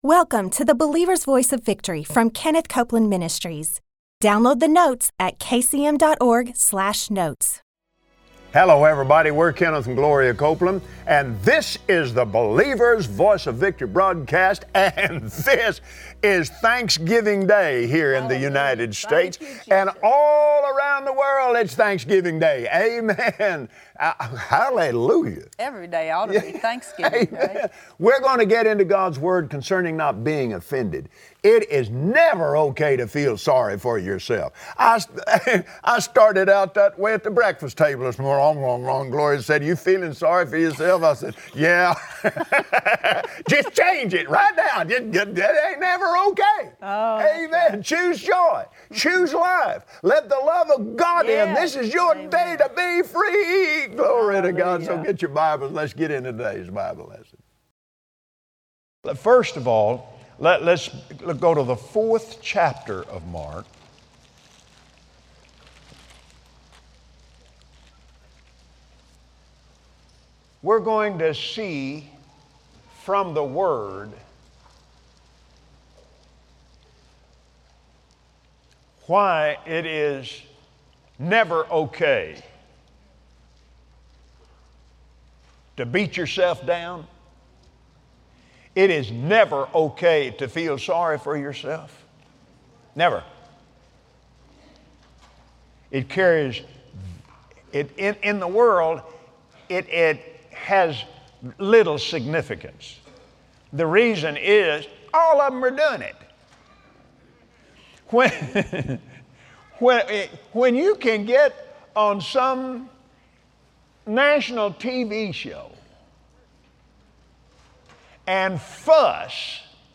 Join Kenneth and Gloria Copeland for an encouraging message on living blessed, healed and free every day. It begins with a choice.